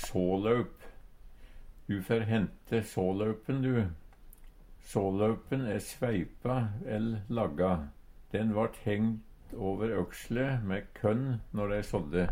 sålaup - Numedalsmål (en-US)